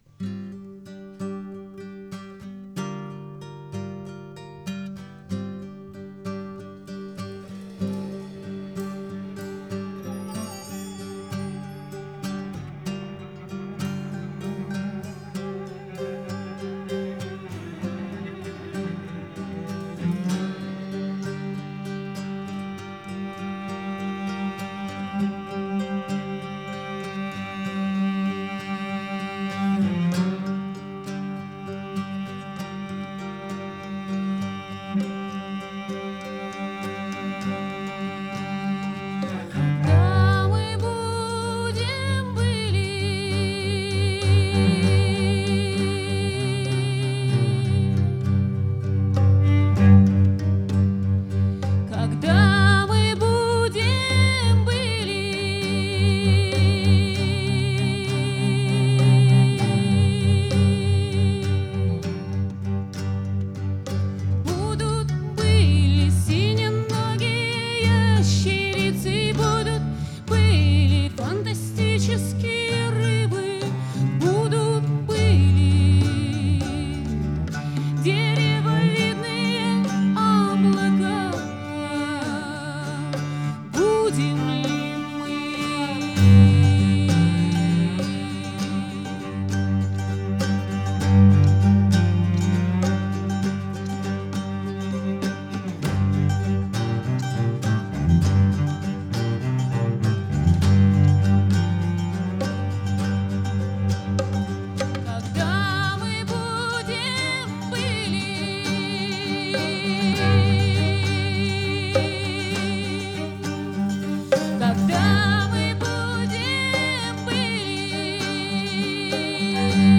Концертный двойник, стиль — акустика.
голос, гитара
виолончель, голос
флейты, гитары, перкуссия, голос
мандолина, голос